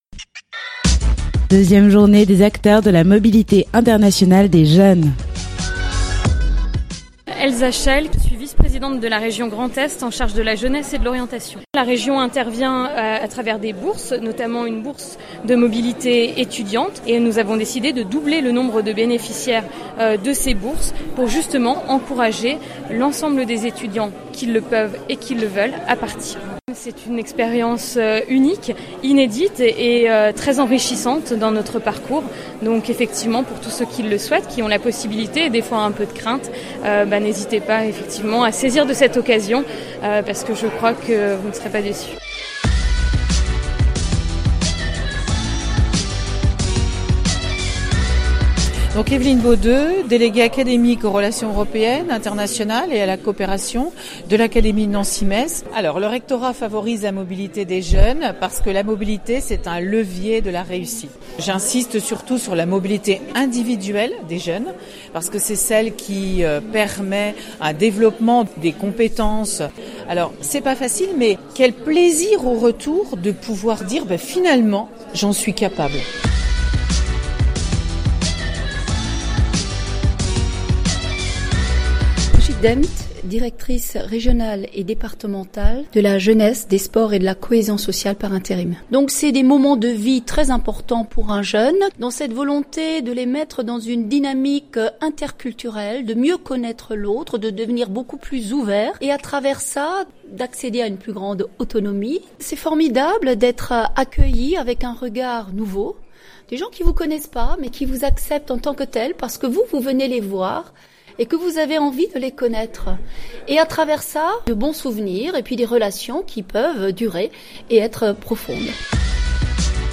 Le mercredi 4 octobre dernier, une journée de rencontre et d’échange  autour de la mobilité international des jeunes était organisée à Tomblaine.
Radio Campus Lorraine a réalisé un rendu sonore de cette journée :
Rendu Sonore court